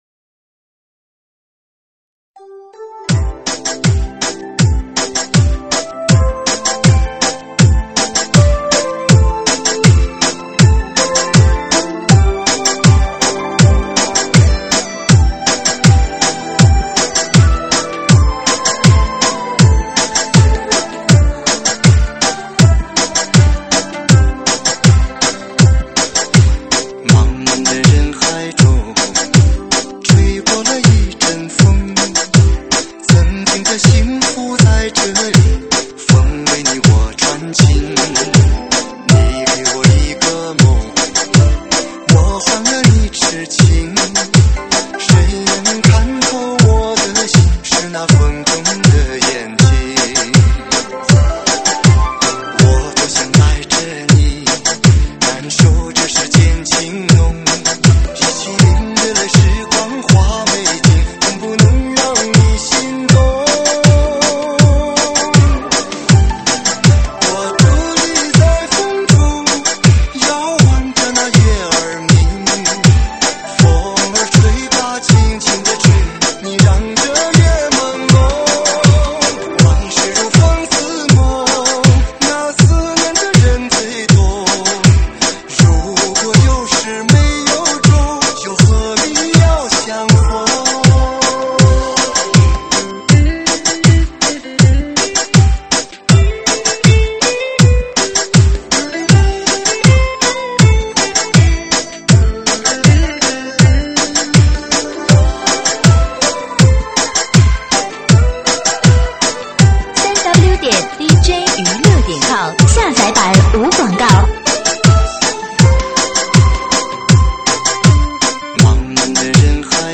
舞曲类别：水兵舞